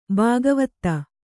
♪ bāgavatta